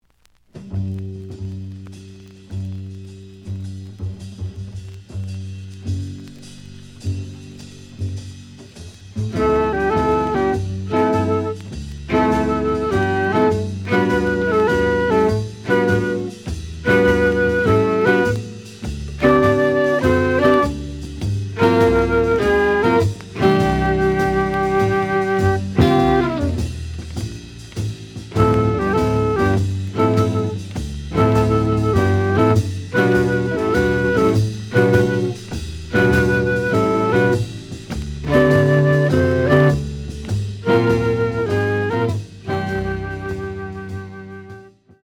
The audio sample is recorded from the actual item.
●Genre: Modern Jazz